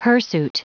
Prononciation du mot hirsute en anglais (fichier audio)
Prononciation du mot : hirsute